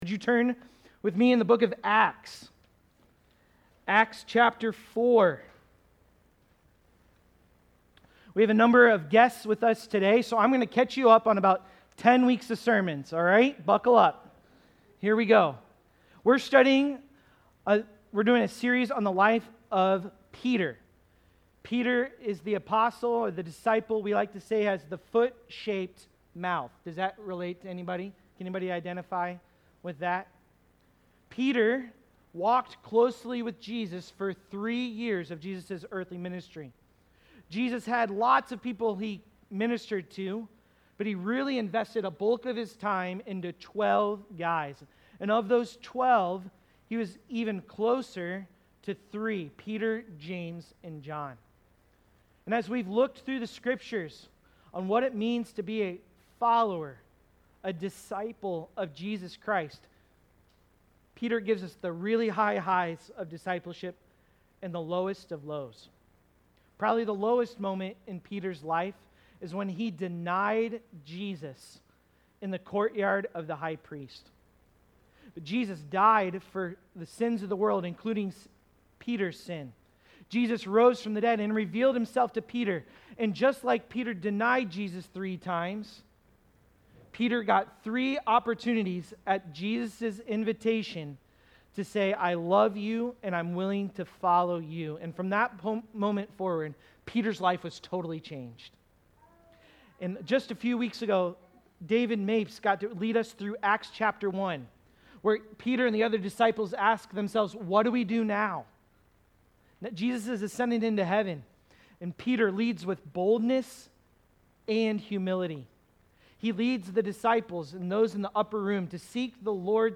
Great-Power-Great-Boldness-Sermon-Audio.mp3